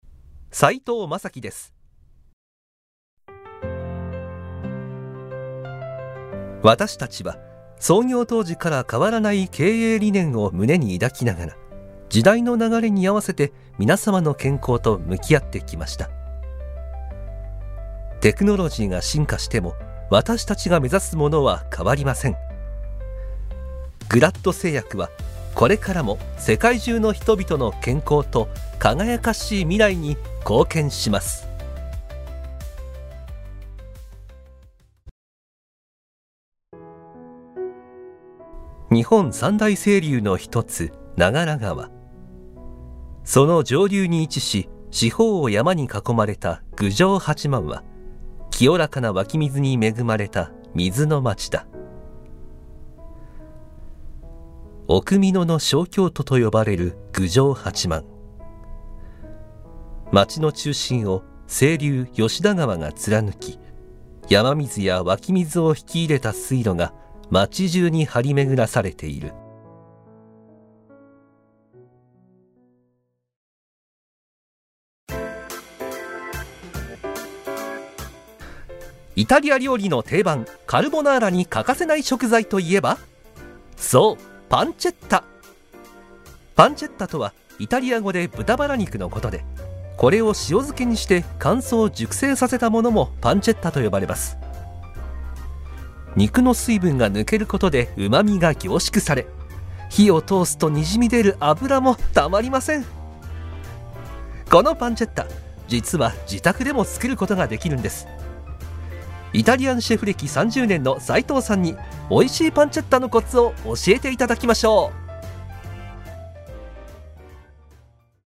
ボイスサンプル
• 声ににじみ出る優しさ
• 音域：高～中音
• 声の特徴：さわやか、優しい